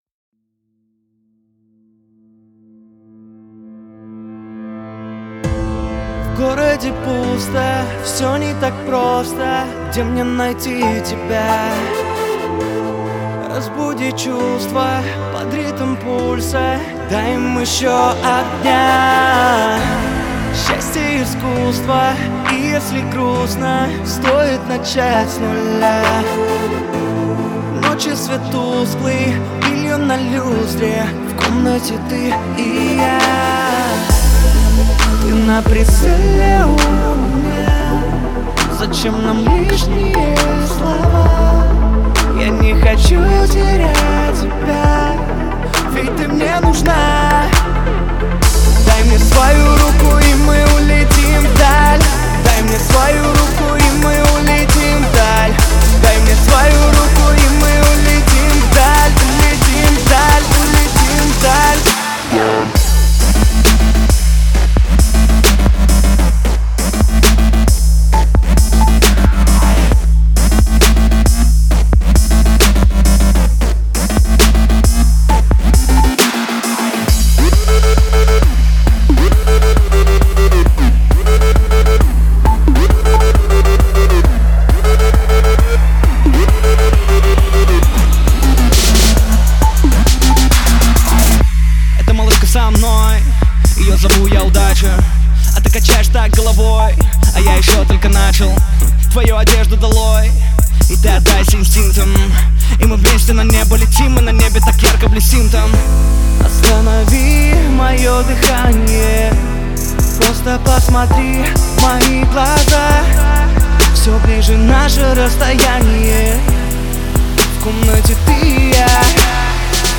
Главная » Файлы » Русский рэп 2016